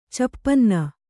♪ cappanna